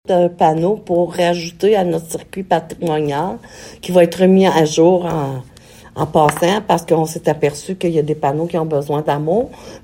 Voici la mairesse de Maniwaki, Francine Fortin :